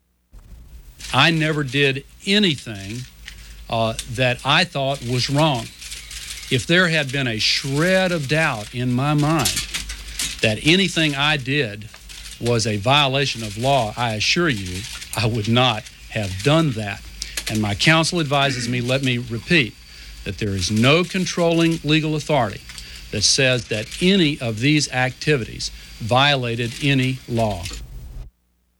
Vice President Al Gore tells a press conference that he broke no written or unwritten laws soliciting funds for the Democrats in the 1996 election campaign.
Campaign funds--Law and legislation Finance Presidents--Election United States Material Type Sound recordings Language English Extent 00:00:29 Venue Note Broadcast on PBS-TV News Hour, Mar. 3, 1997.